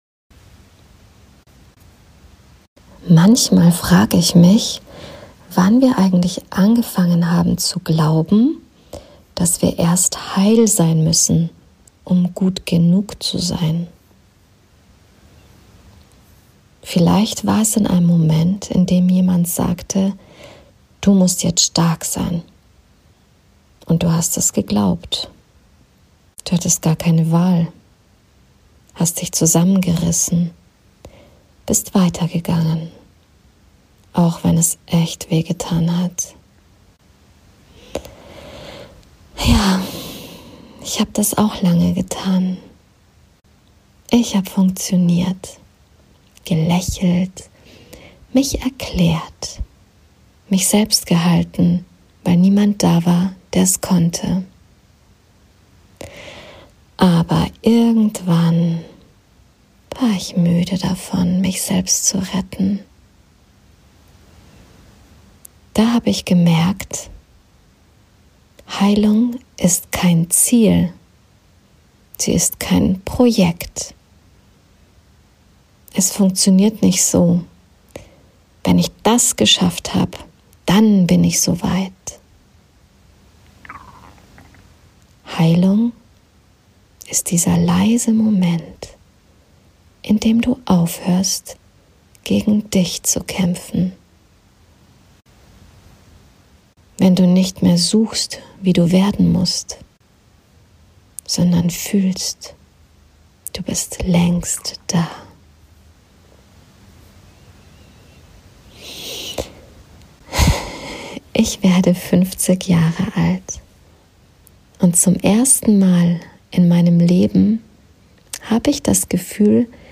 In dieser Folge teile ich mit Dir, was Heilung für mich heute bedeutet – nach fünf Jahrzehnten voller Suchen, Loslassen und Wiederfinden. Ich spreche darüber, wie Frieden entsteht, wenn Du aufhörst, Dich zu reparieren, und anfängst, Dich zu halten. Eine poetische Folge für stille Abende, für Tage, an denen Du einfach nur da sein möchtest.
Ein leises Gespräch über das Fühlen, das Bleiben und das Heimkehren zu Dir selbst.